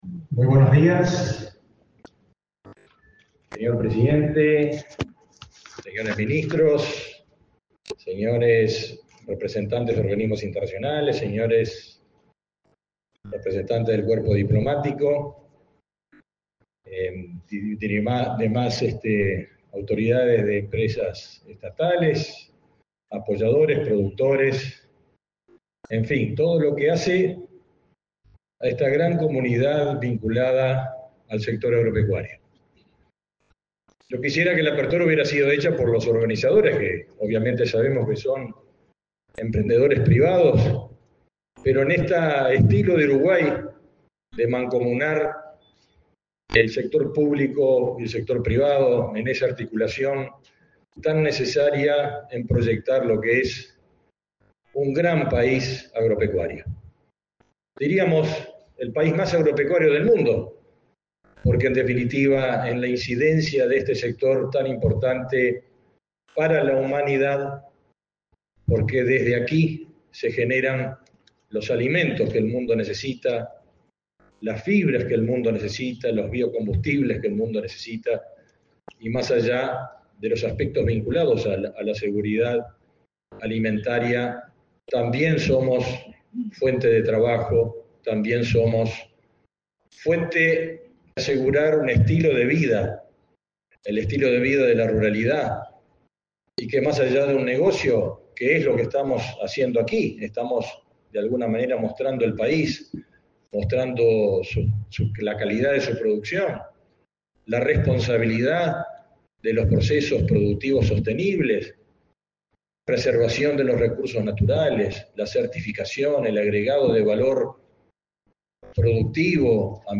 Palabras del ministro de Ganadería, Agricultura y Pesca, Fernando Mattos
Palabras del ministro de Ganadería, Agricultura y Pesca, Fernando Mattos 05/02/2025 Compartir Facebook X Copiar enlace WhatsApp LinkedIn Con la presencia del presidente de la República, Luis Lacalle Pou, se realizó, este 5 de febrero, la apertura del foro Uruguay Sostenible en la exposición Agro en Punta, en el Centro de Convenciones de Punta del Este. En el evento, disertó el ministro de Ganadería, Agricultura y Pesca, Fernando Mattos.